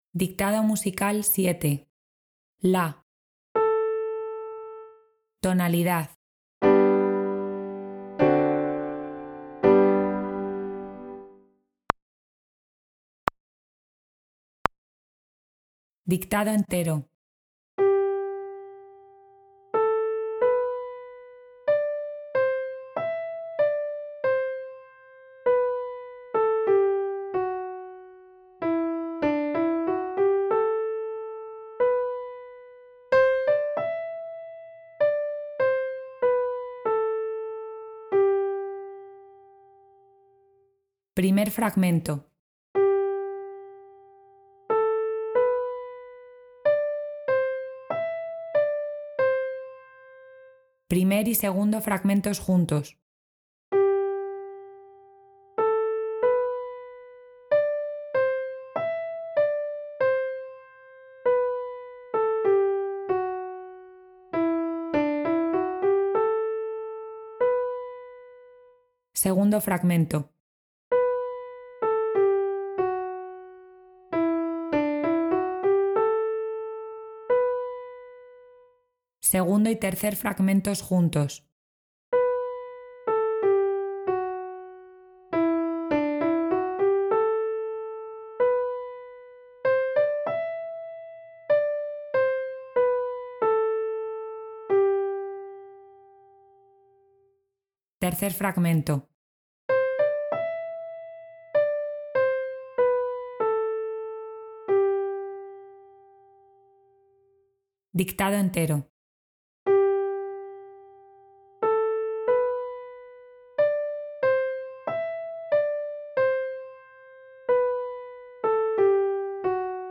A continuación vas a encontrar un ejercicio de dictado musical en PDF para que te lo descargues y puedas resolverlo con su audio correspondiente.
Primero escucharás la nota La como sonido de referencia, posteriormente se tocarán los acordes de la tonalidad en la que nos encontramos y el dictado entero. El ejercicio se dictará por fragmentos y al finalizar escucharemos de nuevo el dictado entero para comprobar el resultado final de nuestra escritura.